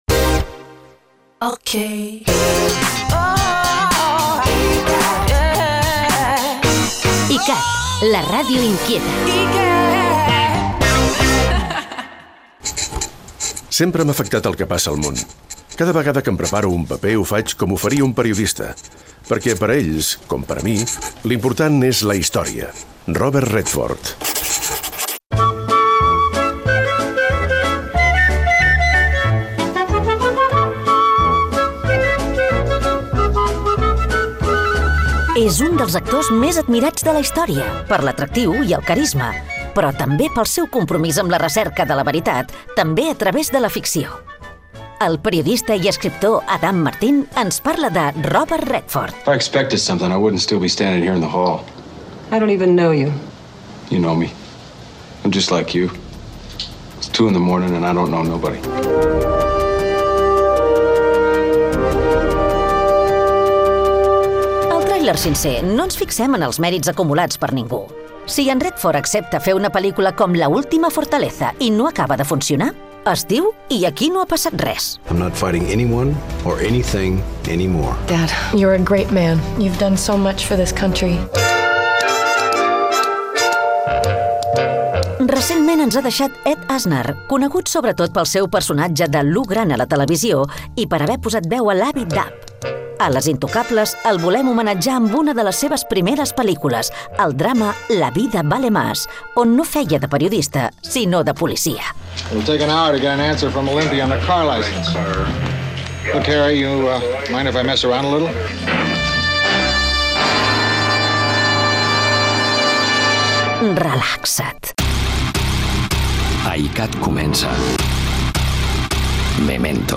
df3532b4a6990c77d03b7e081fb344c444261ed0.mp3 Títol iCat Emissora iCat Cadena Catalunya Ràdio Titularitat Pública nacional Nom programa Memento Descripció Indicatiu de l'emissora, frase de l'actor Robert Redford. Careta del programa. Repàs als inicis de la trajectòria de l'actor Robert Redford